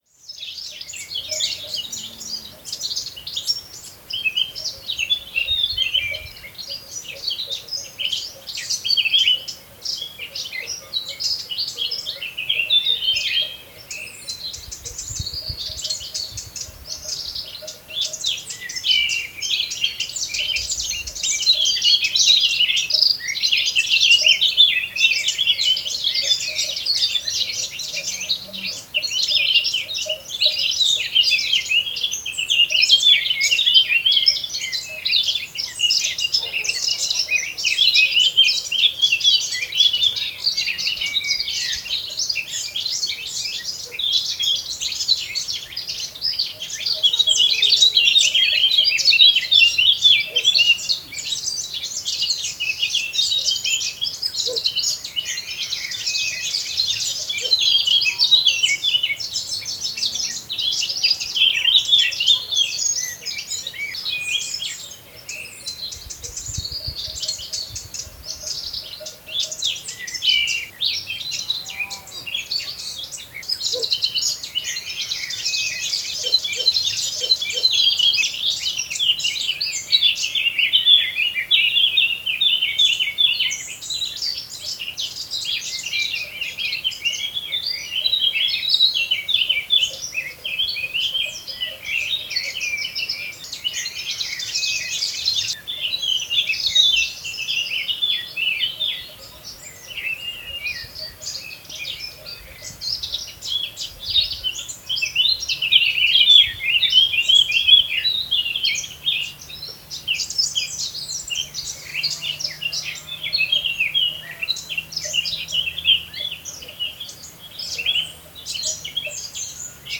La orquesta de los Valles Pasiegos no descansa y toca para nosotros en ausencia de ruidos de procedencia humana.
Y ahora, déjate llevar por la imaginación, vuélvete a colocar los auriculares y disfruta del paisaje sonoro del interior de nuestro territorio…”